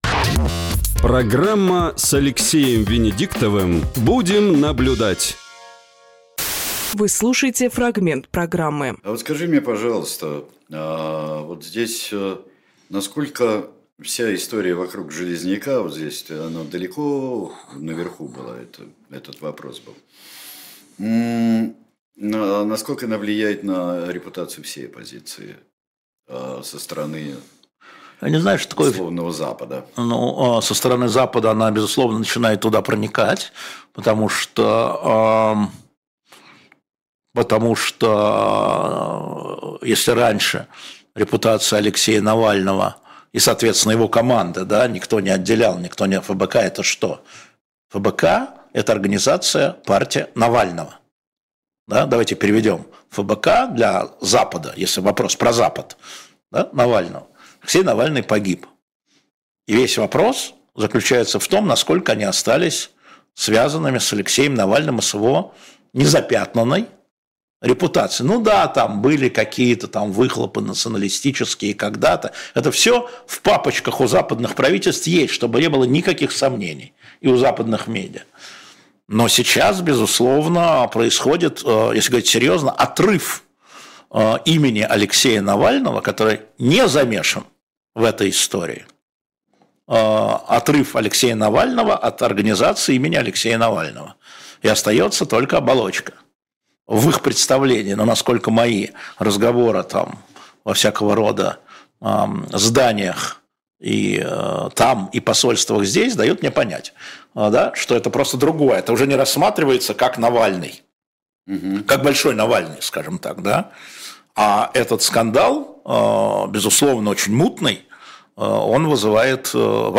Алексей Венедиктовжурналист
Сергей Бунтманжурналист
Фрагмент эфира от 09.11.24